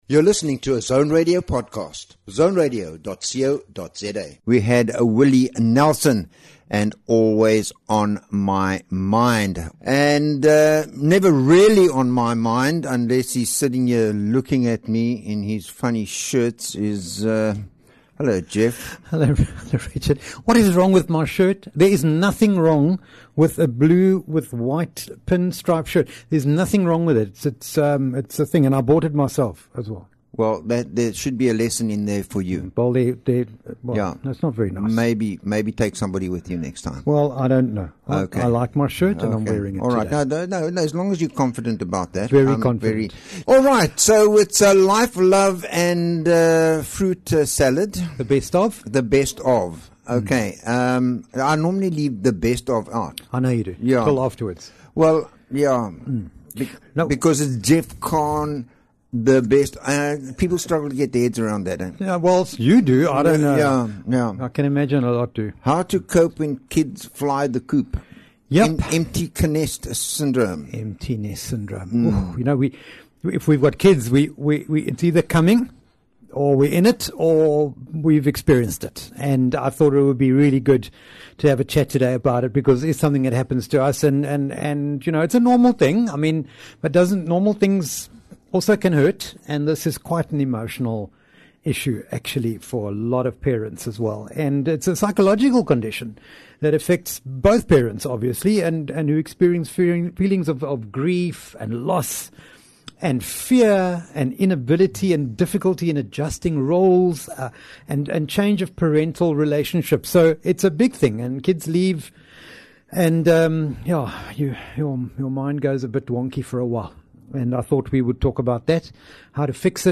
is a bi-weekly radio show exploring the rich tapestry of human experiences. Delve into the sweet and tangy moments of life, savoring conversations on relationships, wellbeing, and the flavors that make up our existence.